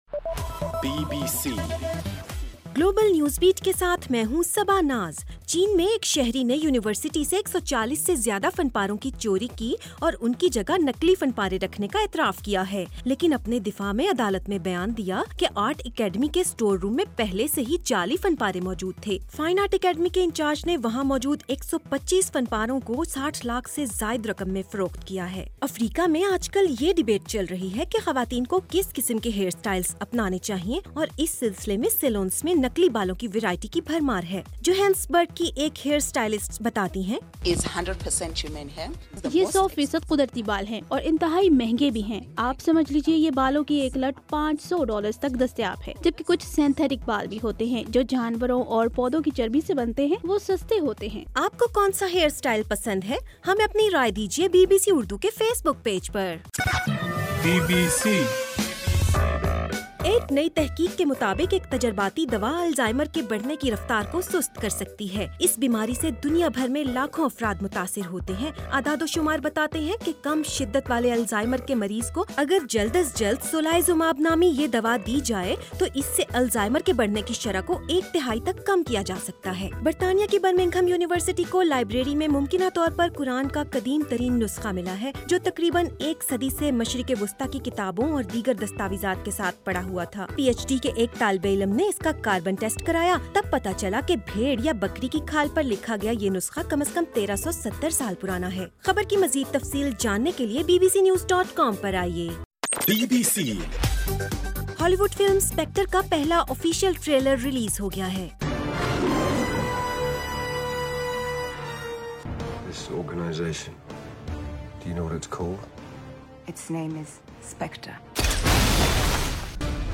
جولائئ 22 رات 11 بجے کا نیوز بلیٹن